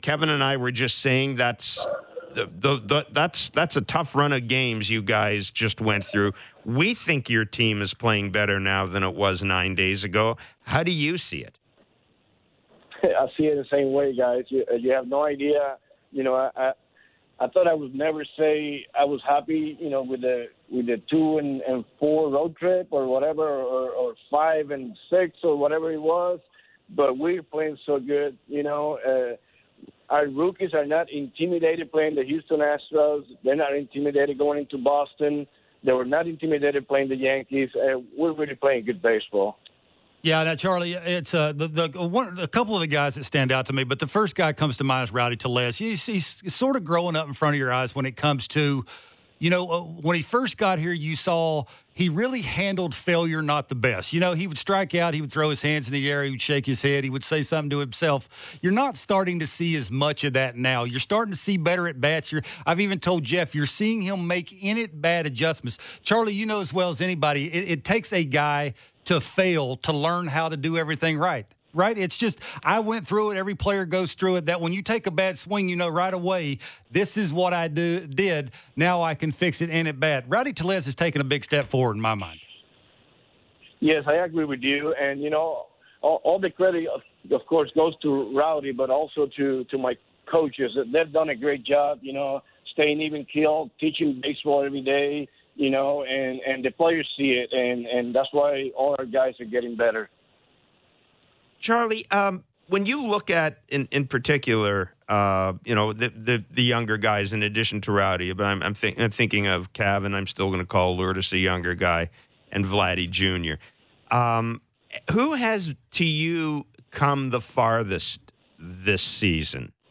Charlie Montoyo spoke during Baseball Central, heaping praise onto players like Cavan Biggio and Lourdes Gurriel Jr. for how they have attacked the Blue Jays’ season so far.